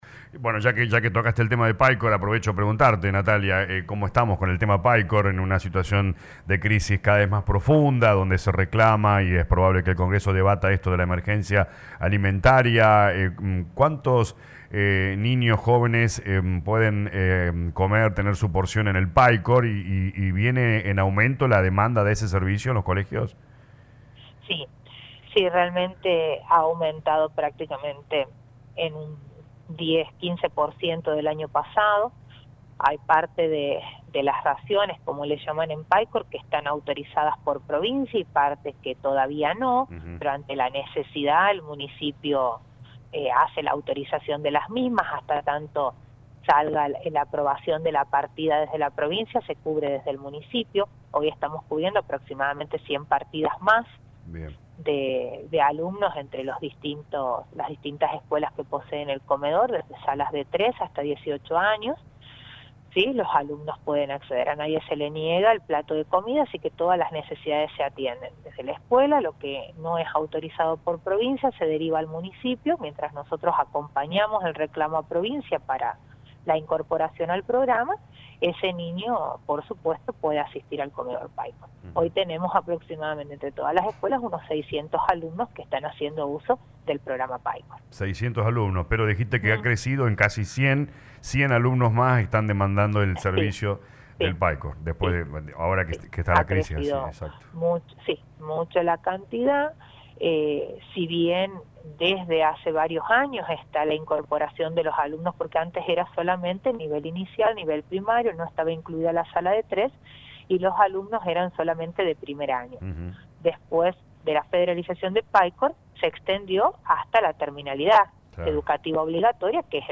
Así lo indicó la secretaria de educación y relaciones institucionales del gobierno de la ciudad, Ing Natalia Bosio, en diálogo con LA RADIO 102.9.